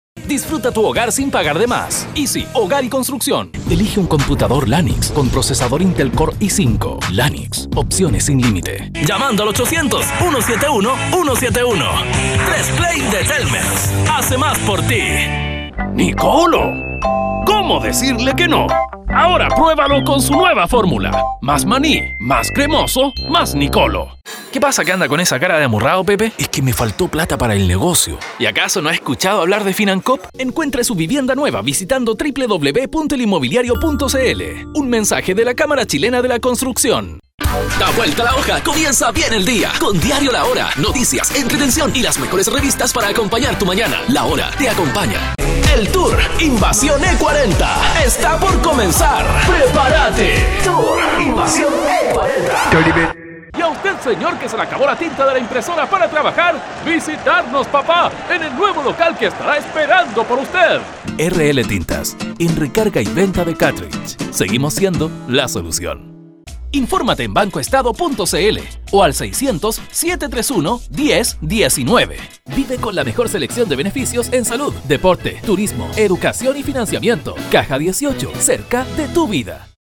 Locutor Comercial
Mi misión sera interpretar el guión redactado y representar con mi voz una marca o darle vida a un personaje determinado.
demo-mix-locutorcomercial.mp3